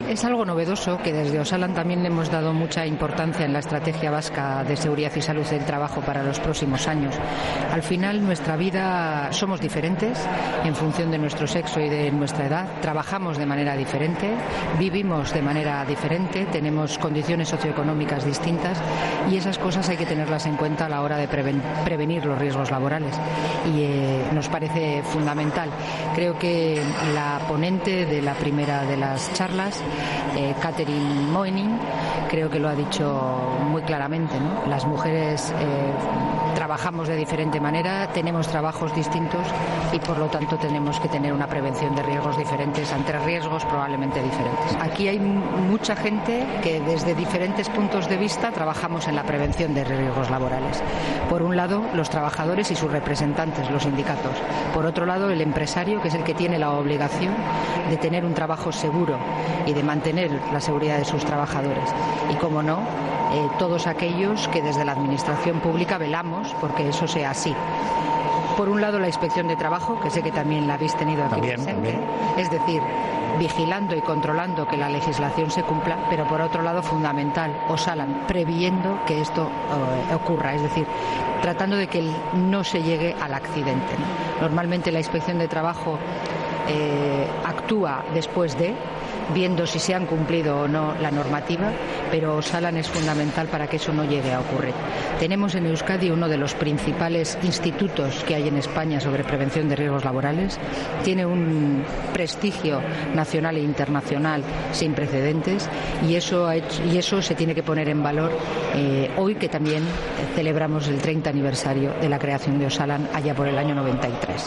Precisamente a esa perspectiva de género y a la edad se ha referido Elena Pérez, viceconsejera de Trabajo y directora de Osalan, en la entrevista que le hemos realizado en Onda Vasca, con motivo de este V Congreso Internacional de Seguridad y Salud en el Trabajo.